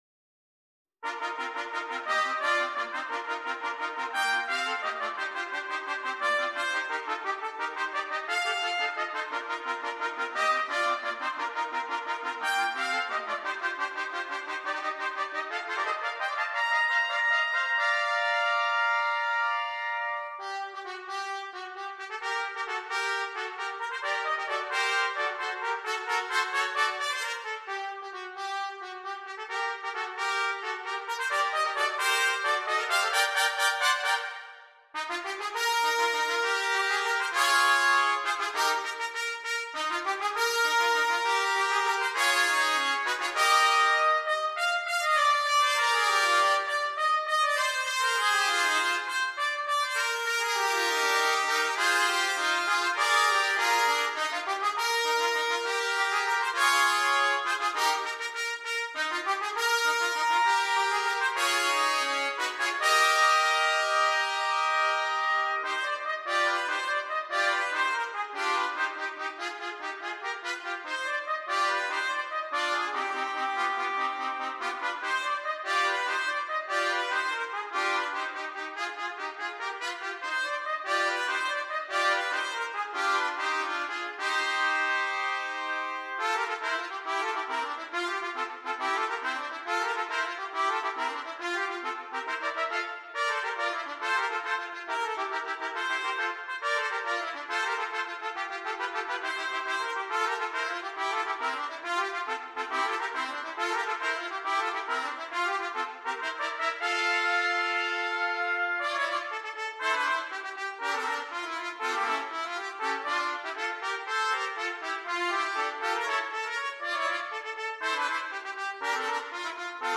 Brass
3 Trumpets
They are each short and in a variety of styles and keys.